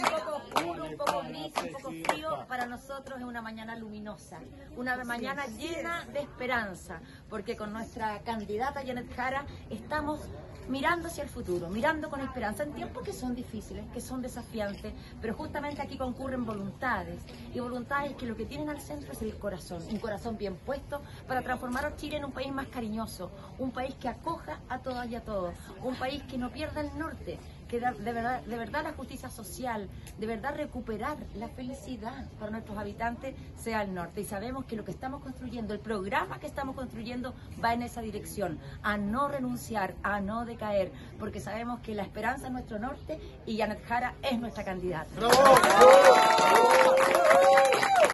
En la Casa Museo Michoacán de los Guindos, en la comuna de La Reina, la candidata presidencial Jeannette Jara, recibió de manos del Equipo de Coordinación Nacional del partido Acción Humanista su propuesta para nutrir el futuro programa de Gobierno, de cara a las primarias presidenciales de Unidad por Chile, el 29 de junio.